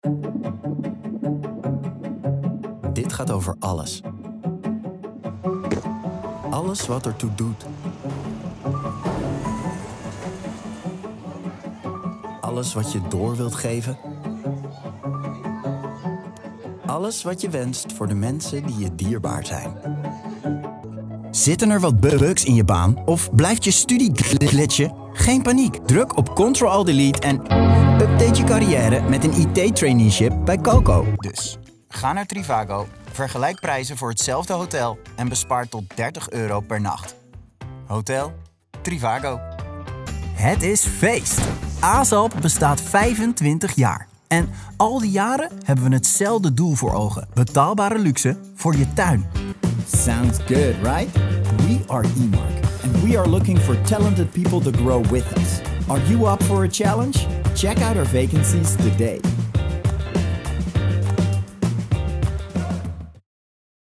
Distinctive, Playful, Accessible, Versatile, Friendly
Corporate